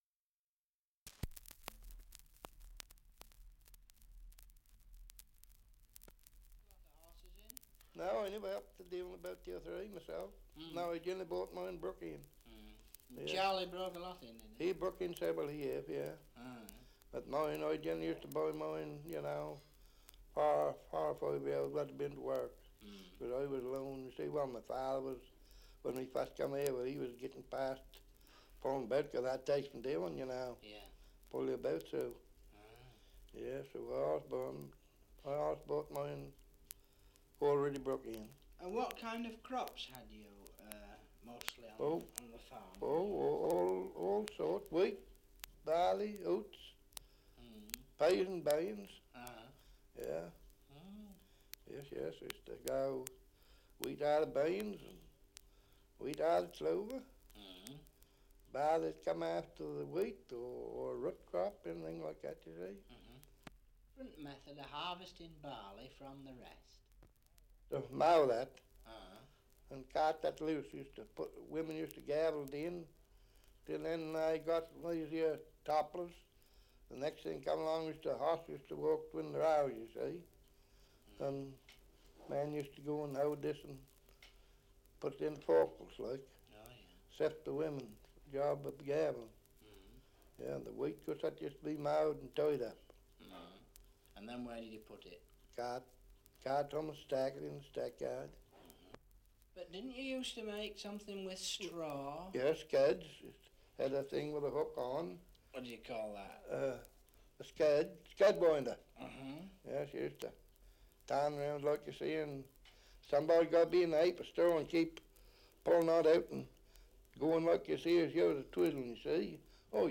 Survey of English Dialects recording in Mendlesham, Suffolk
78 r.p.m., cellulose nitrate on aluminium